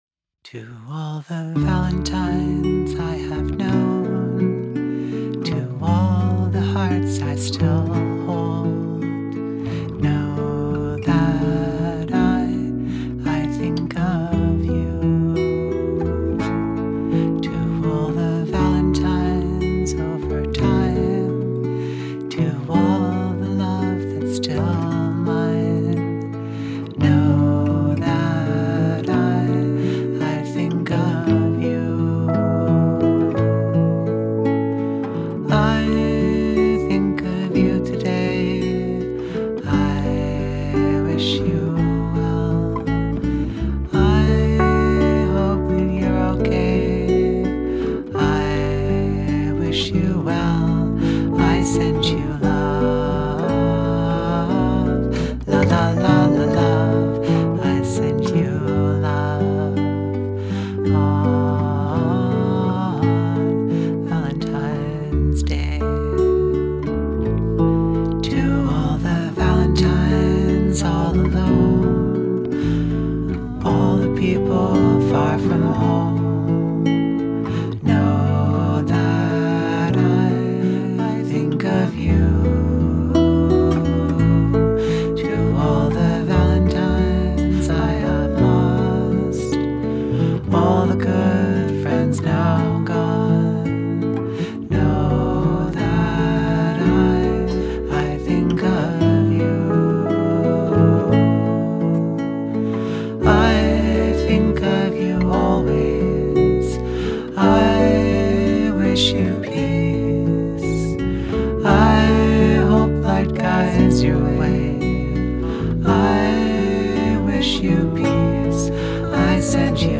I love these harmonies.
The lalalala is fun.